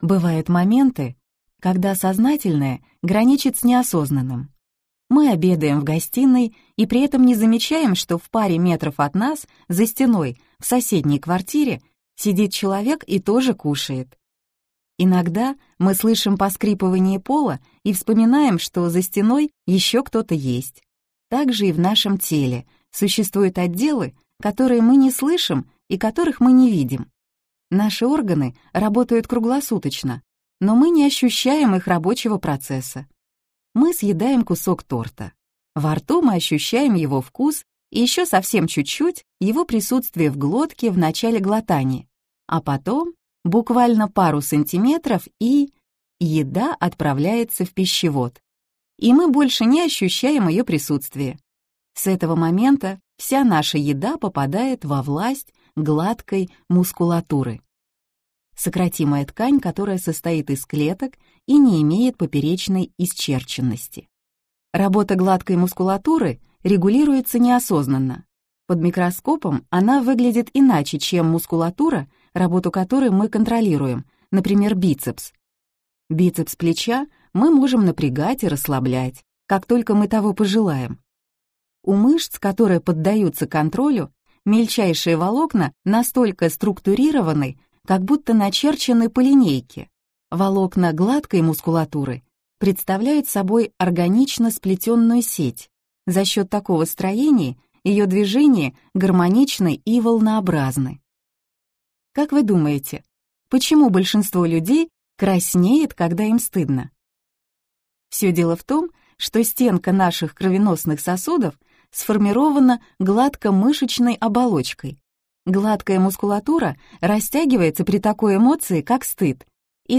Аудиокнига Очаровательный кишечник.